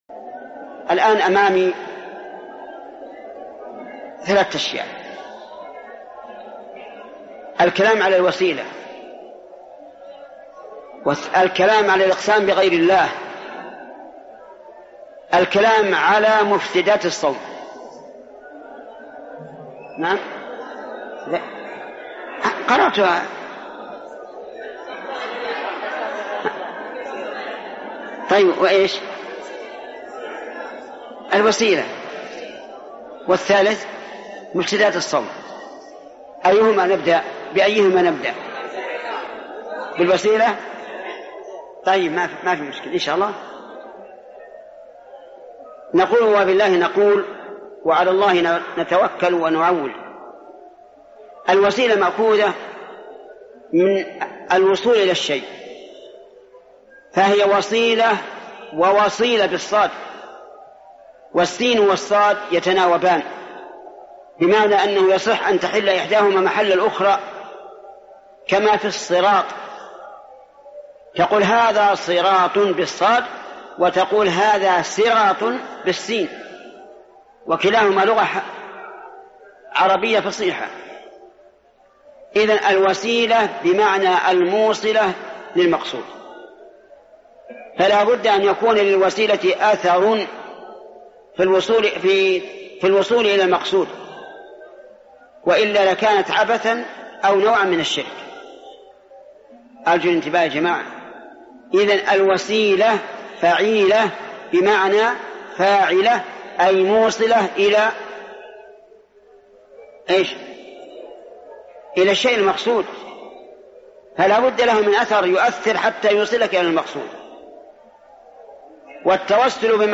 ارسل فتوى عبر "الواتساب" ينبوع الفتاوى فتاوى ودروس في المسجد الحرام - الــــــعــــقـــيــدة - الشيخ محمد بن صالح العثيمين المادة لقاء[103 من 219] التوسل المشروع والتوسل الممنوع؟